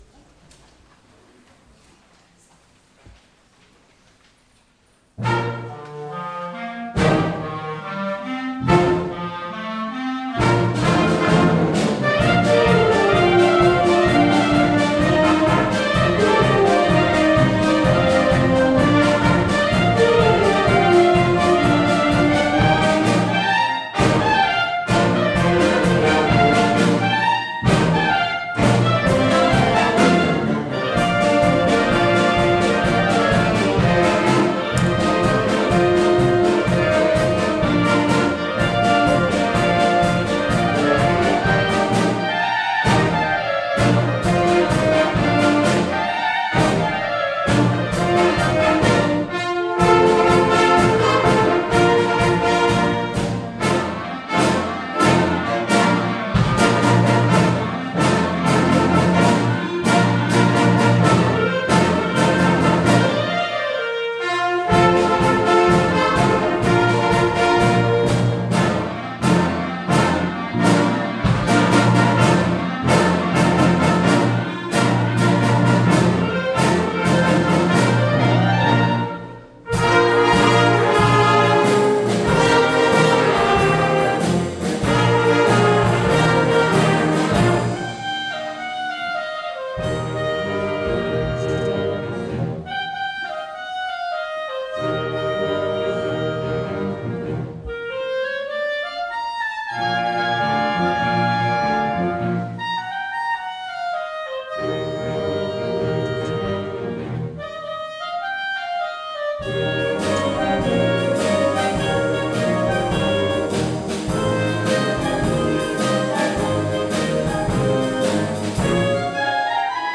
clarinets